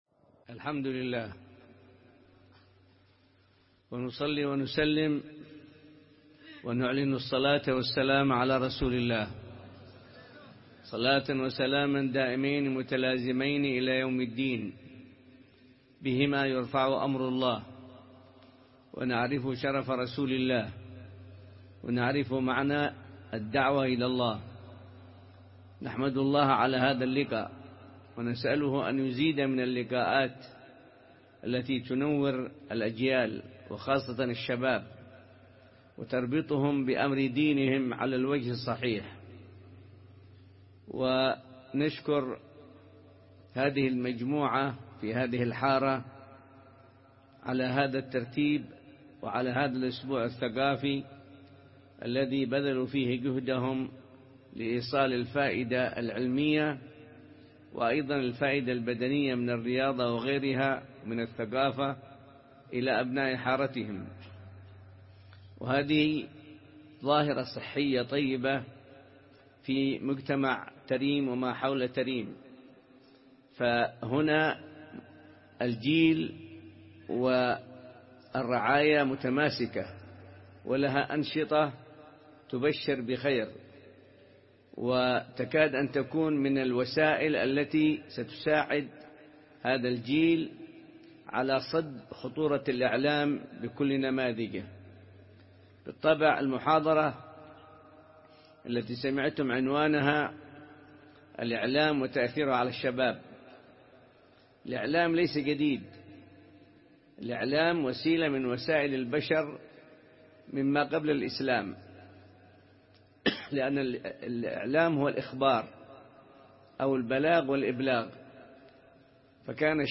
بساحة مسجد الإمام أبي بكر السكران – تريم – حضرموت ضمن فعاليات الأسبوع الثقافي الأول الذي ينظمه فريق الاتحاد بالمجف بمدينة تريم تحت شعار «بتثقيف شبابنا تنهض أمتنا»
محاضرة